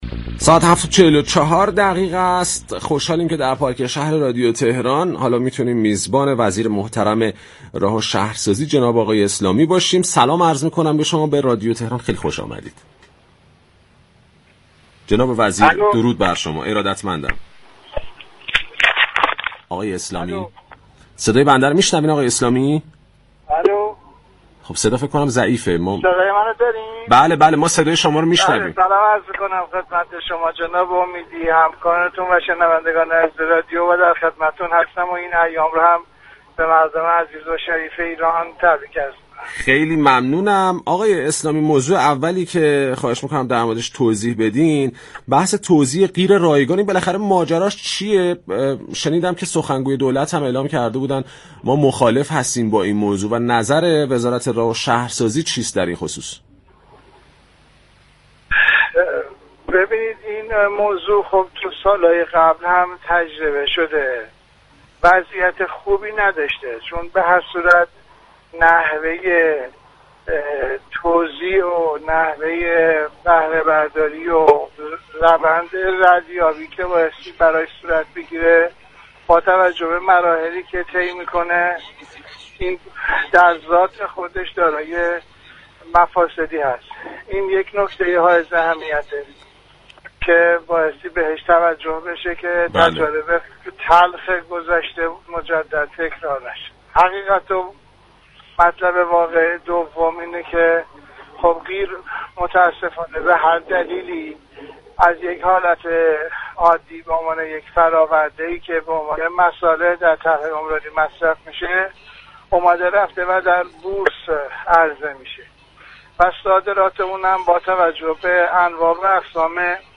محمد اسلامی، وزیر راه و شهرسازی در گفتگو با پارك شهر موضوعات توزیع و حواله رایگان قیر، طرح مسكن ملی، وام ودیعه اجاره و اطلاعات جعبه سیاه هواپیمای مسافربری اوكراینی را به اختصار تشریح كرد.